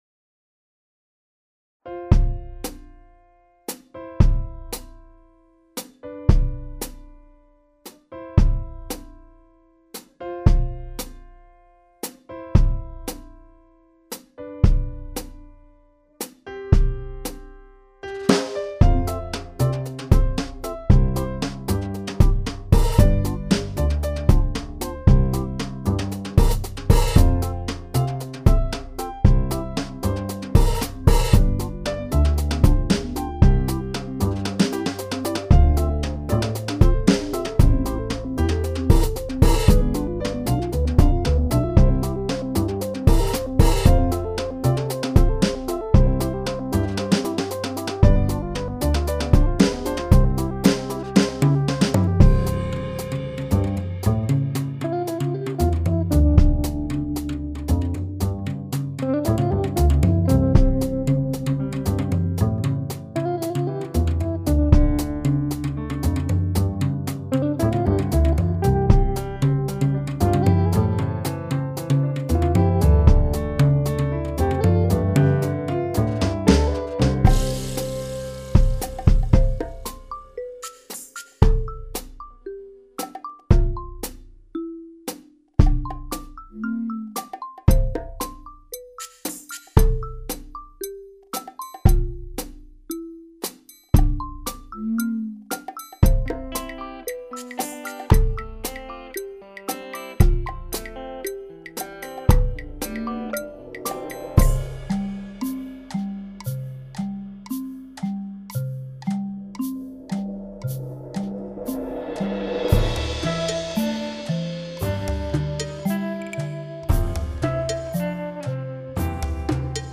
some soundtrack music for a short video